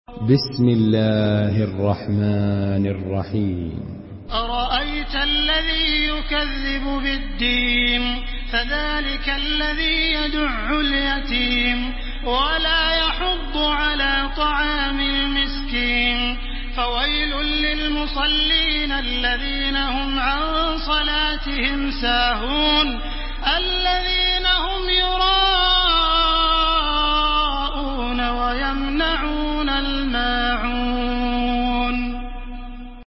تحميل سورة الماعون بصوت تراويح الحرم المكي 1430
مرتل